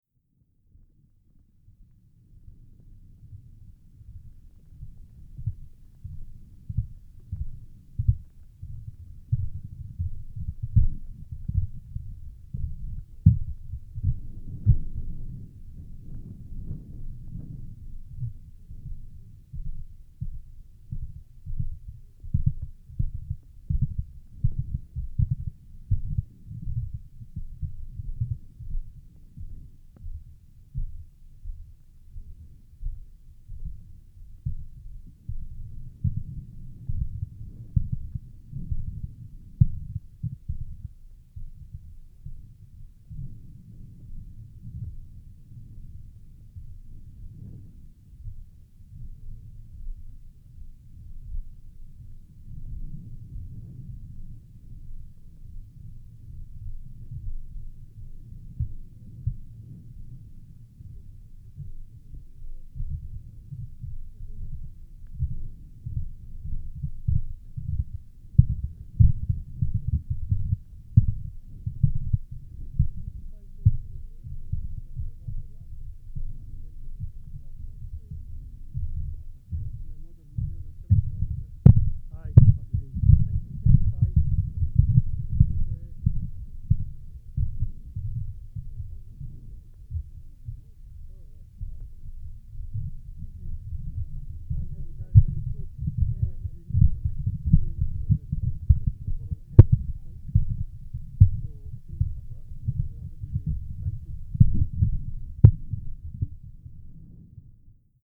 Skara Brae, Europe's most remarkably preserved Neolithic village, offers a profound connection to the distant past. In this recording, the wind carries its timeless song, mingling with the murmurs of visitors as they explore the site. Captured through the Geofon, the vibrations of wind and footsteps resonate like the ancient village's heartbeat, while the muffled conversations evoke echoes of lives once lived amidst these stone walls.